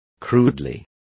Complete with pronunciation of the translation of crudely.